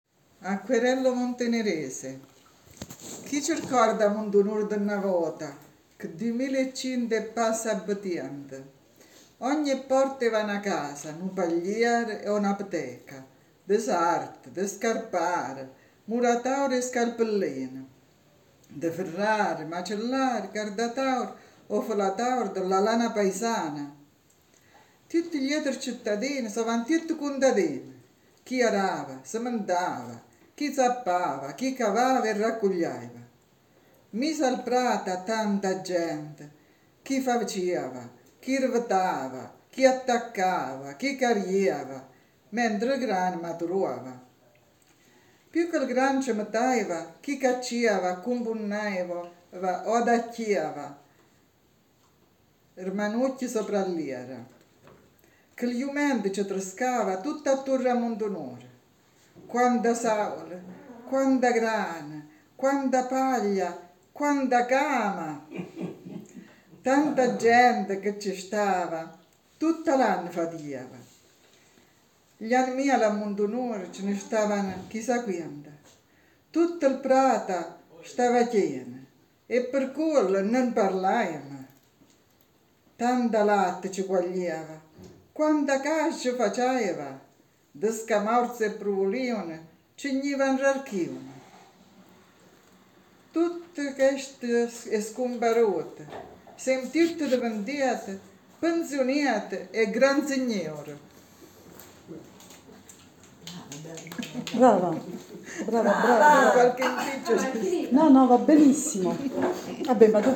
Il gruppo che recita le poesie in dialetto e che canta le due canzoni è composto da